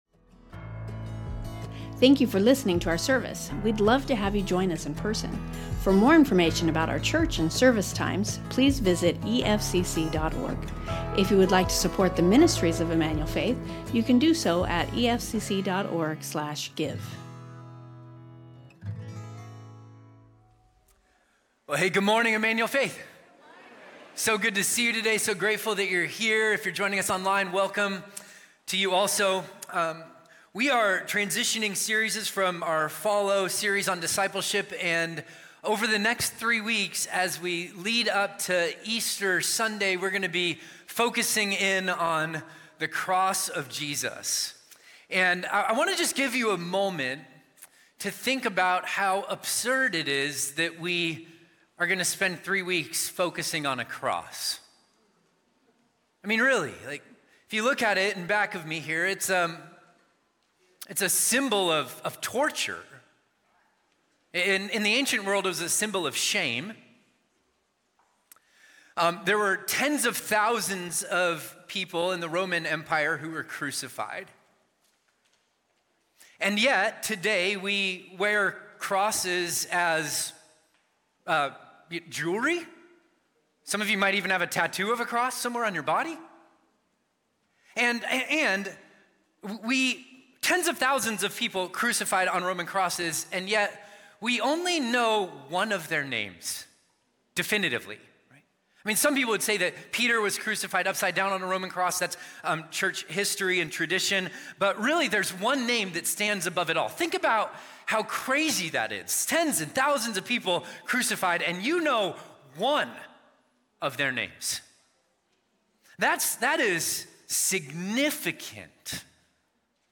Emmanuel Faith Sermon Podcast The Victory of the Cross | Colossians 2:13-15 Mar 16 2026 | 00:43:07 1x 00:00 / 00:43:07 Subscribe Share Spotify Amazon Music RSS Feed Share Link Embed